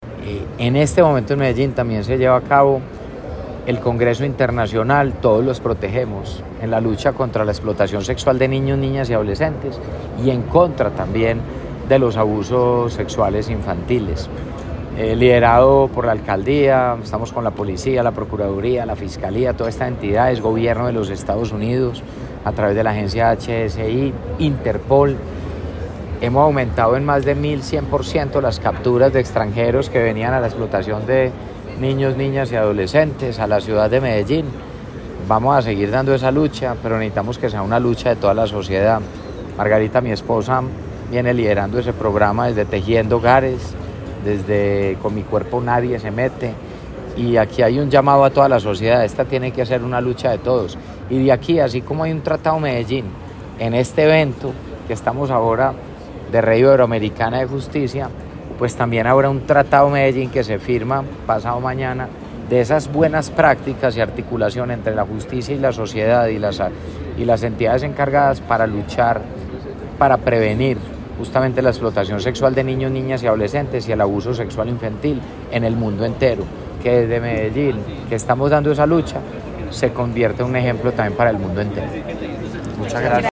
Hasta el 20 de noviembre, Medellín será sede del Congreso Internacional Todos los Protegemos, que sesiona en Plaza Mayor, un evento clave en la defensa de la niñez y la adolescencia frente a las violencias sexuales.
AlcaldeMedellin_Congreso_Todoslosprotegemos_01.mp3